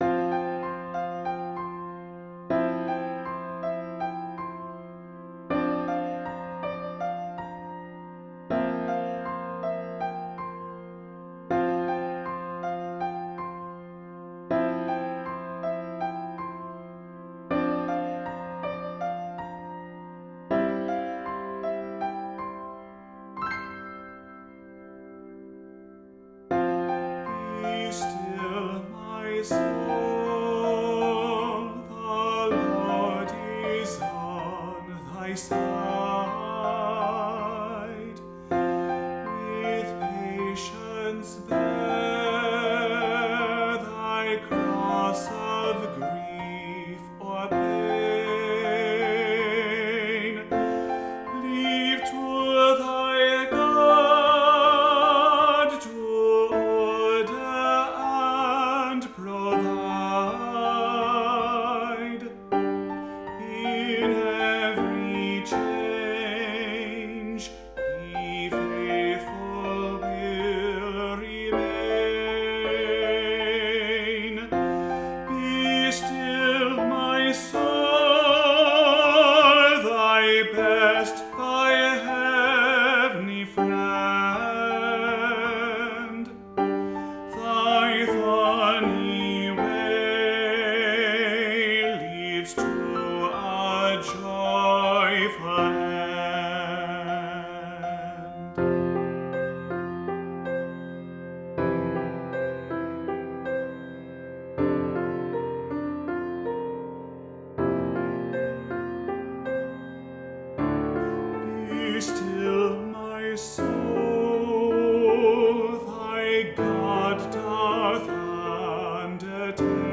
Voicing: Solo & Kbd